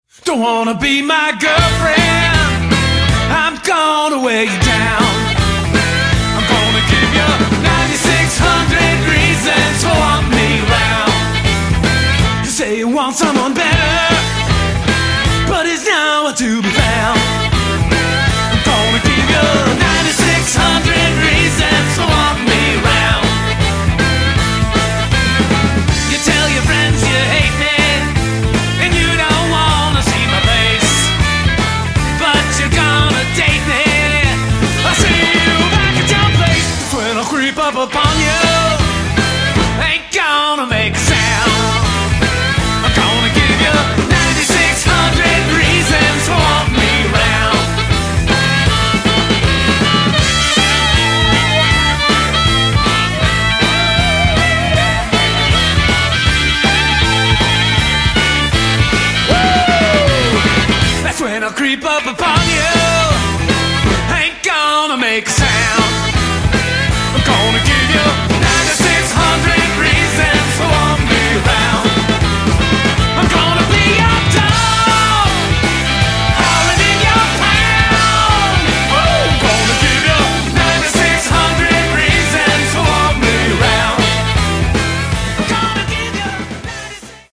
MASTER RECORDINGS - Rock
('60s Garage Rock)
loud, crass, catchy and quick!